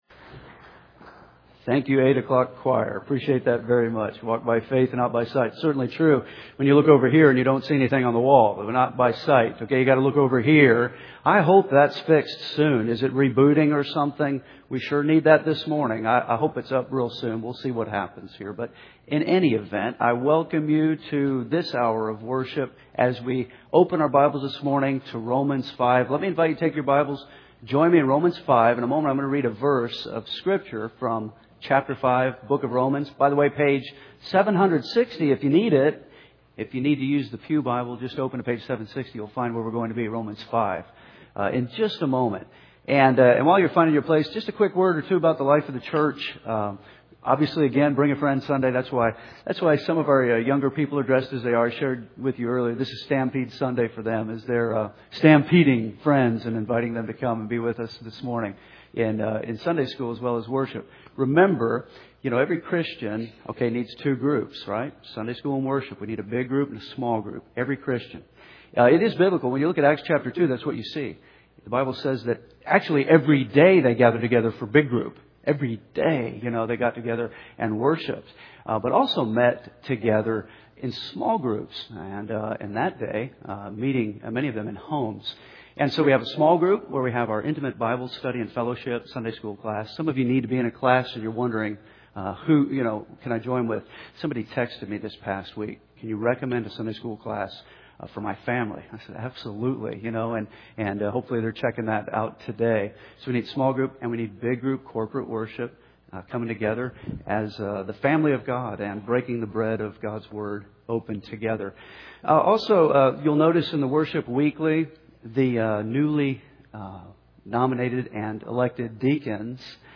Event: Bring-A-Friend Sunday